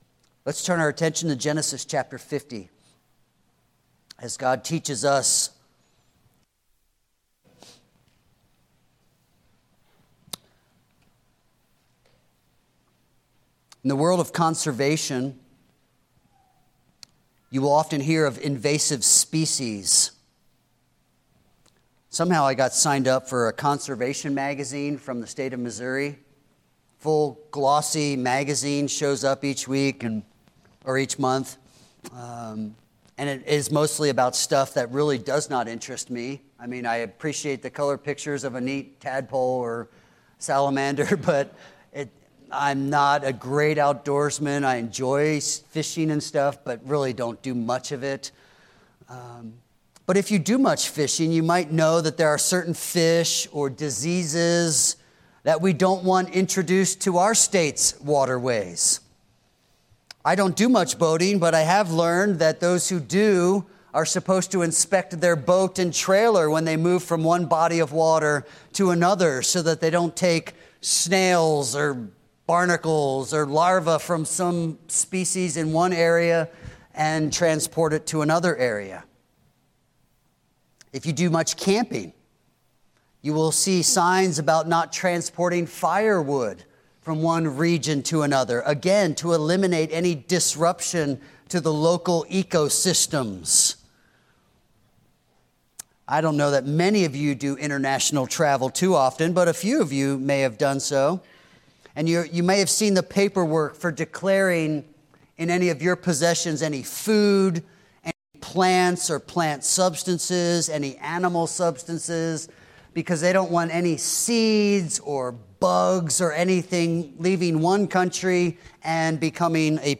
Weekly sermons and Sunday school lessons from Grace Bible Church